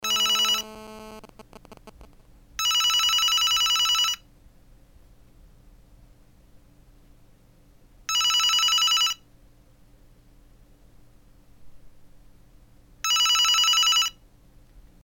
Klingelton der Woche: Audioline G2